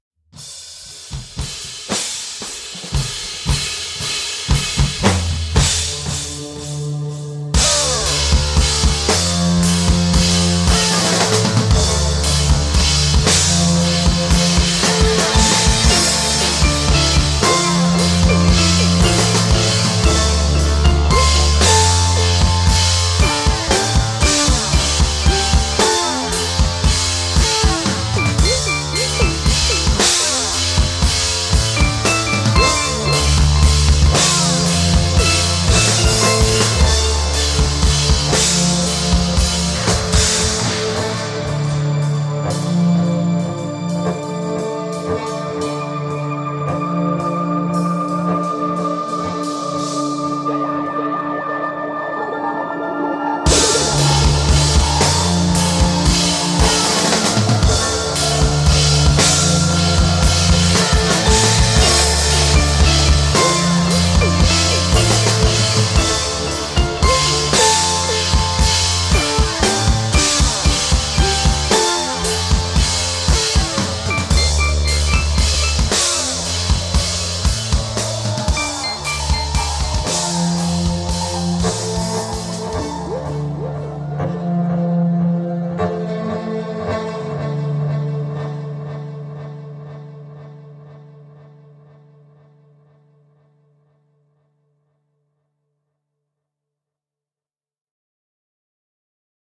Žánr: Rock
Pop/Stoner Rock